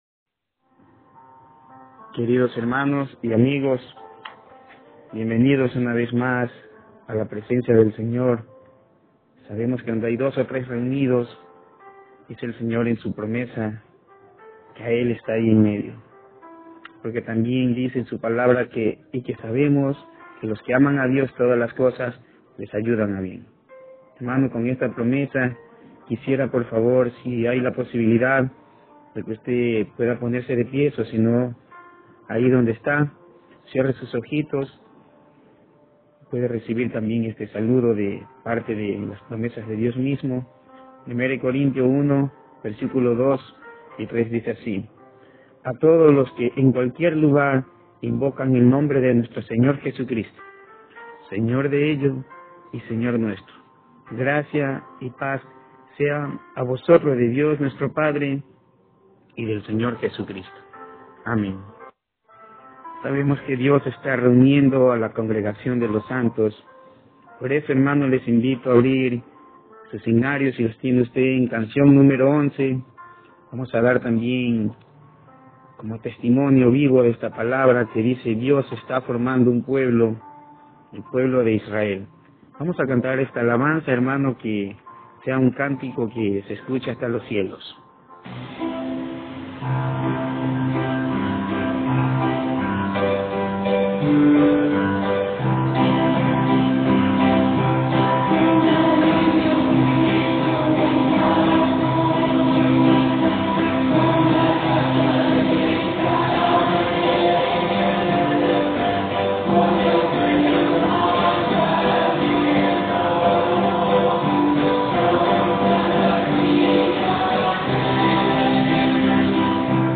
Passage: 1 Corintios 5: 1-8 Tipo: Sermón 1 Corintios 5